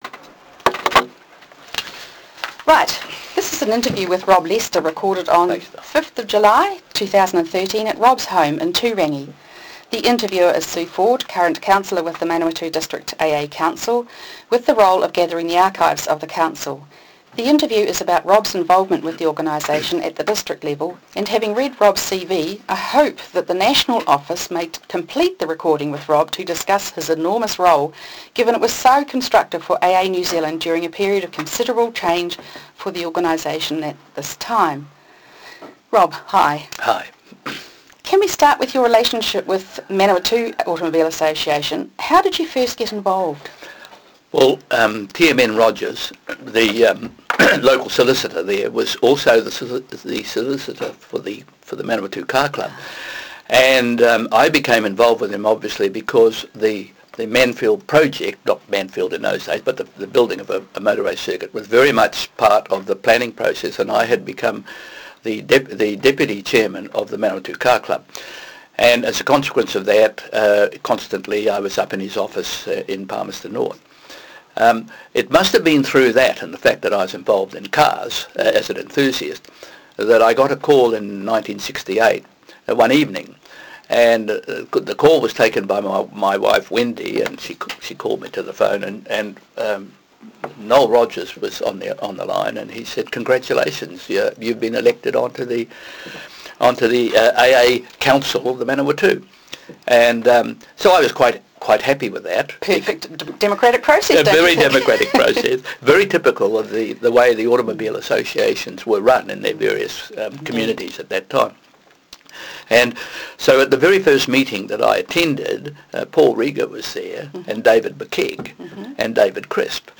Oral interview
Oral Interview